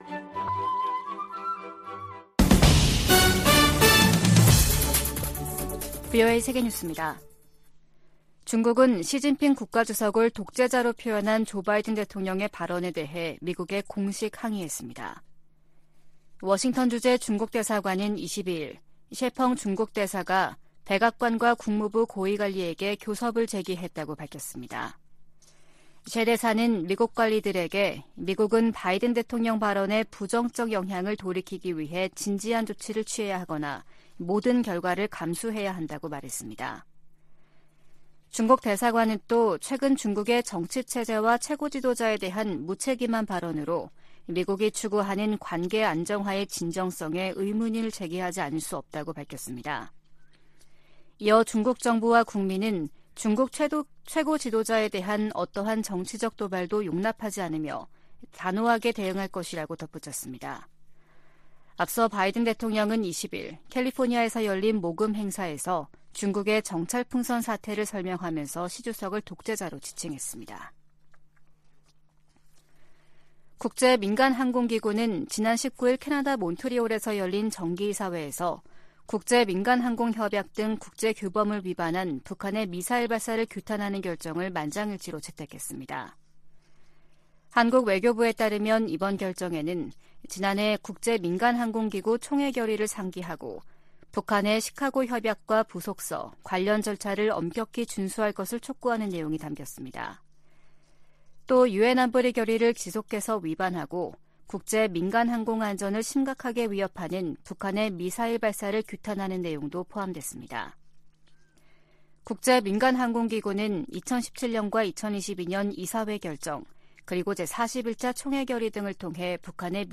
VOA 한국어 아침 뉴스 프로그램 '워싱턴 뉴스 광장' 2023년 6월 23일 방송입니다. 미국 국무부는 중국이 북한 문제를 해결할 역량과 책임이 있다는 점을 거듭 강조했습니다. 미국은 자산 동결 등의 조치를 통해 북한이 탈취 암호화폐를 미사일 프로그램에 사용하는 것을 막았다고 법무부 고위 관리가 밝혔습니다. 주한미군 고고도 미사일 방어체계 즉 사드(THAAD) 기지가 인체에 미치는 영향은 미미하다고 한국 정부의 환경영향 평가가 결론 지었습니다.